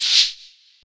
Perc (Xan Shaker)(1).wav